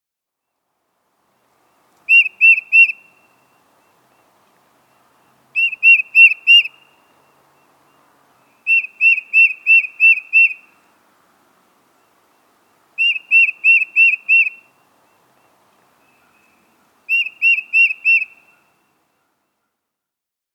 Tufted Titmouse
How they sound: These birds make a high, whistled peter-peter-peter song.